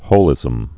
(hōlĭzəm)